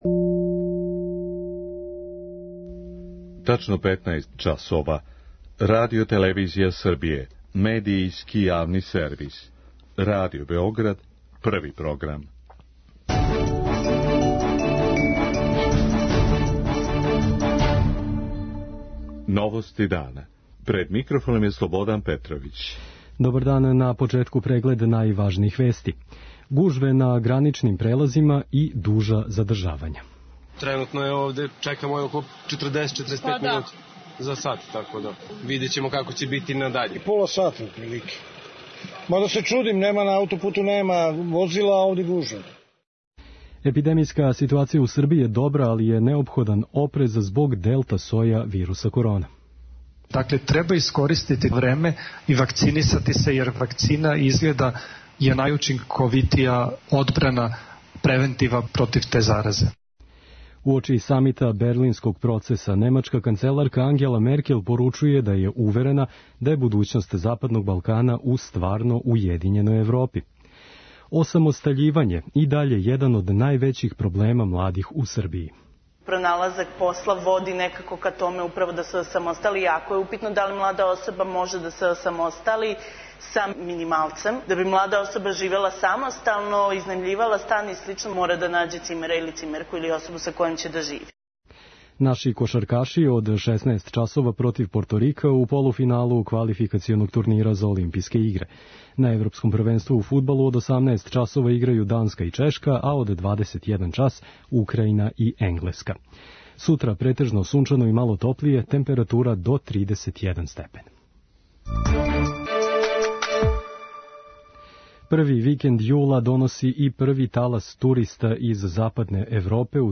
Током ноћи гранични прелаз Прешево прешло је 11.000 путника у више од 2.000 возила. преузми : 6.54 MB Новости дана Autor: Радио Београд 1 “Новости дана”, централна информативна емисија Првог програма Радио Београда емитује се од јесени 1958. године.